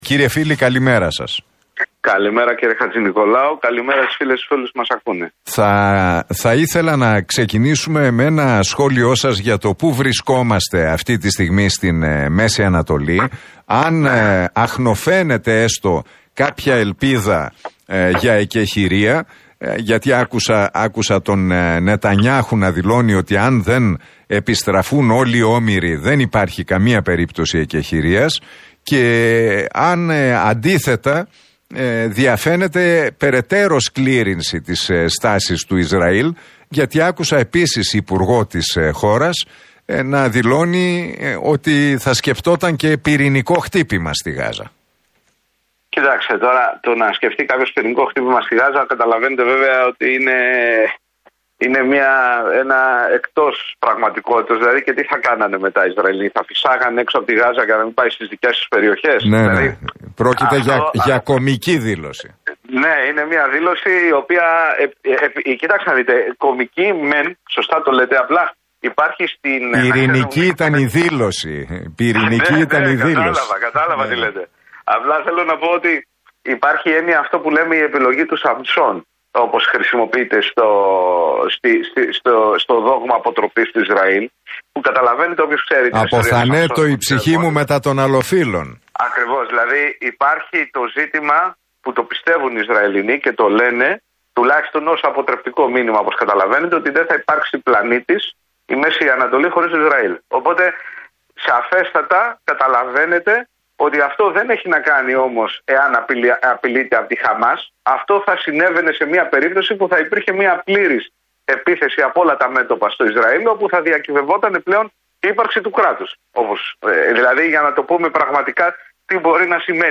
μιλώντας στην εκπομπή του Νίκου Χατζηνικολάου στον Realfm 97,8.